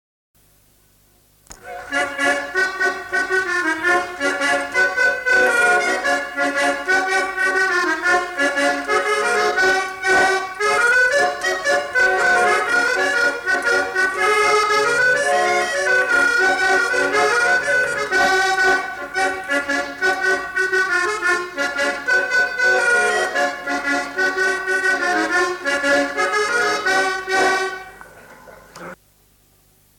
Instrumental. Accordéon diatonique
Lieu : Mas-Cabardès
Genre : morceau instrumental
Instrument de musique : accordéon diatonique
Il joue sans doute une figure de quadrille.